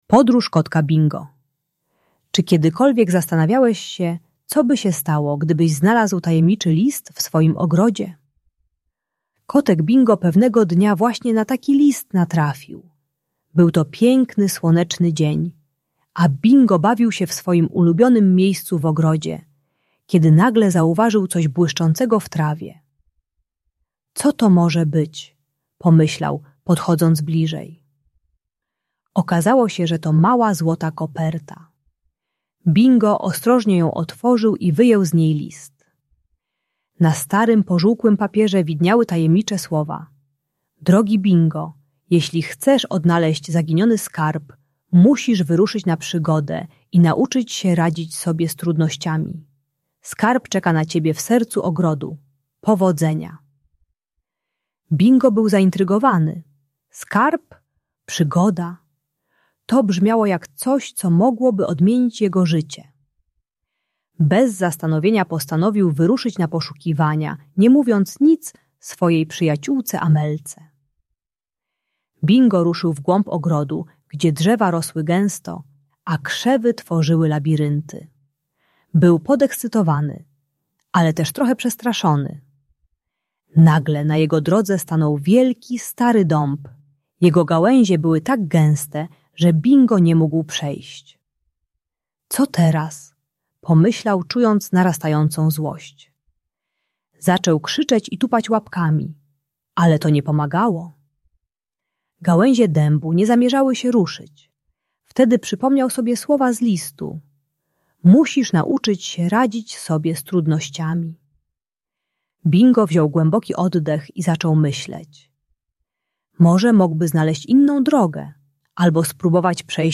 Kotek Bingo uczy się radzić sobie z trudnościami bez krzyku i tupania. Audiobajka o złości i agresji dla przedszkolaka pokazuje technikę głębokiego oddechu i szukania alternatywnych rozwiązań zamiast reagowania złością.